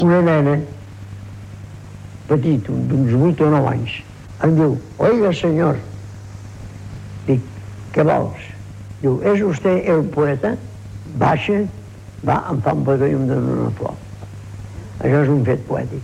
Paraules del poeta Josep Vicenç Foix sobre el fet poètic.
Extret de Crònica Sentimental de Ràdio Barcelona emesa el dia 12 de novembre de 1994.